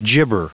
Prononciation du mot gibber en anglais (fichier audio)
Prononciation du mot : gibber